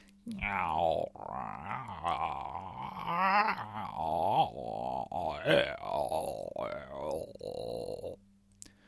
描述：Gnarly vocal snarl loop 付出就有回报功不可没
Tag: 创意 敢-19 循环 口技